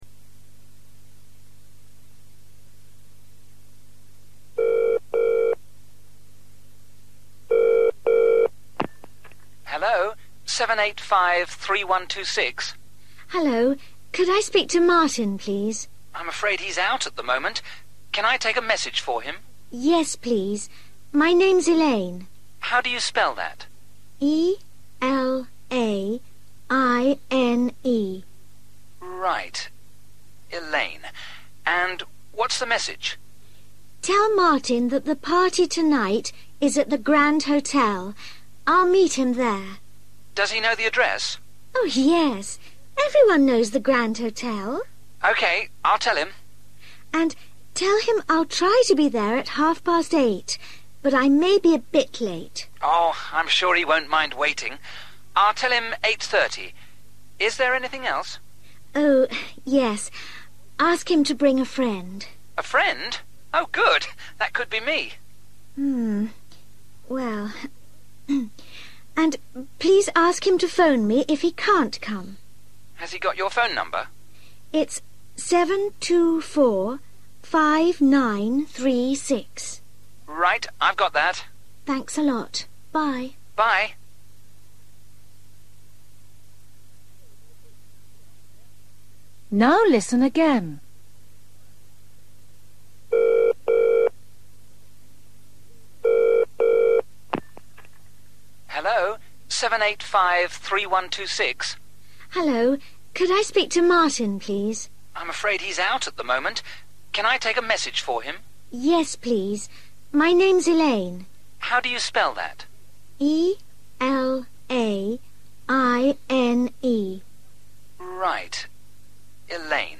You will hear a telephone conversation.
You will hear the conversation twice.